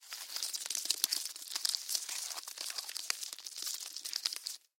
Гусеница скручивает лист в своих лапах